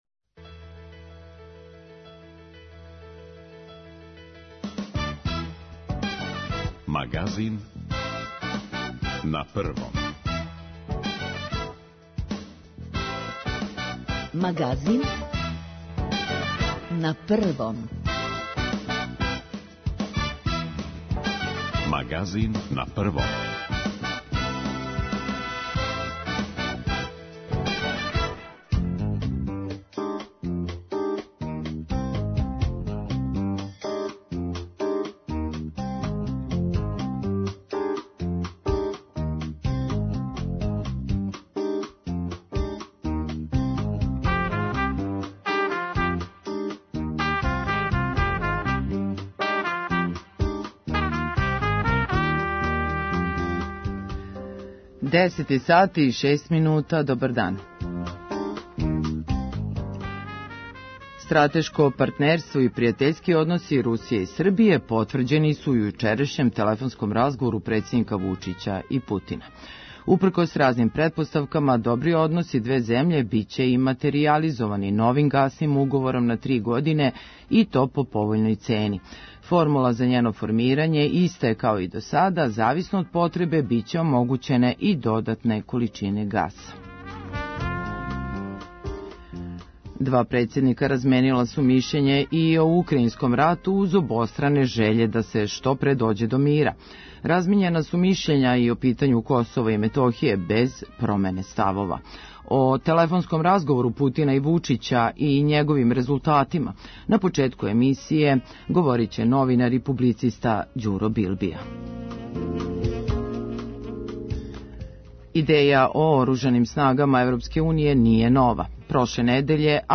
Детаљи ће се утаначити наредних дана са дирекцијом Гаспрома и обећавају мирну зиму. преузми : 30.92 MB Магазин на Првом Autor: разни аутори Животне теме, атрактивни гости, добро расположење - анализа актуелних дешавања, вести из земље и света.